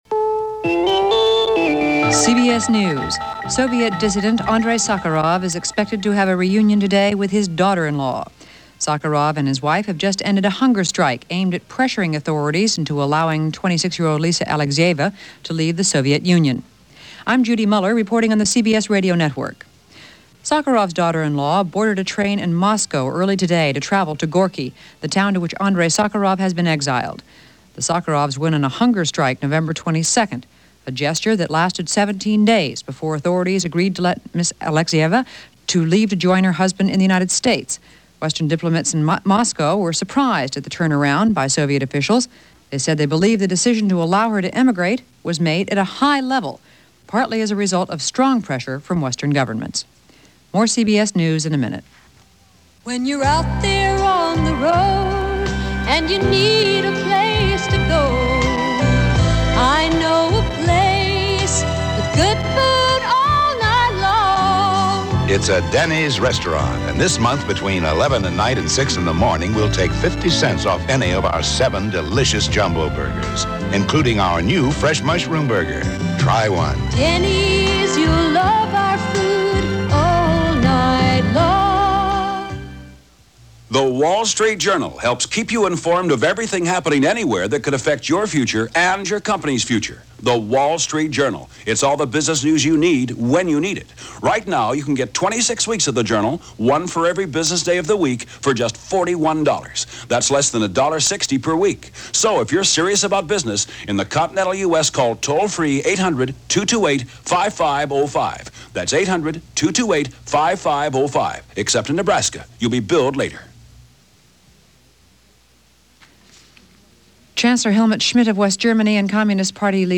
Dissidents, Discussions And Relations - December 12, 1981 - latest news for this day from CBS Radio News On The Hour